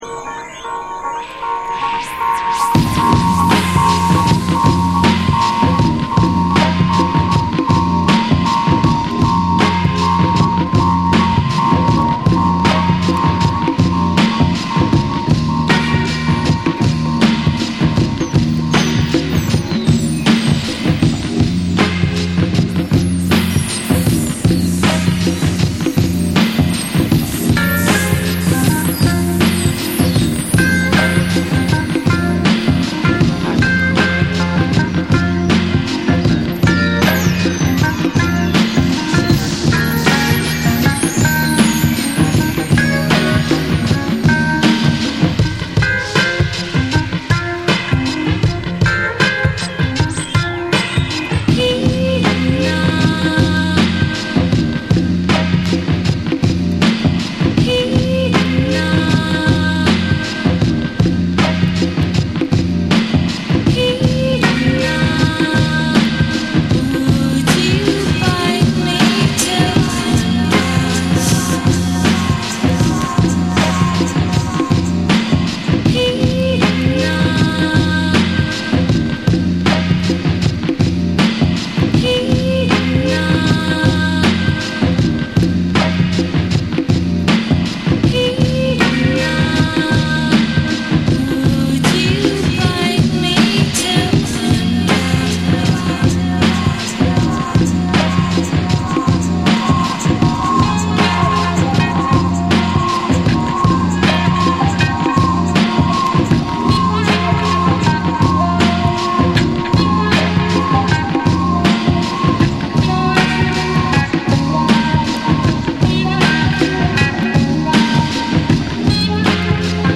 オリジナルのダークでムーディーなトリップ・ホップをよりエレクトロニックに、またはビート重視のアプローチで再構築！
BREAKBEATS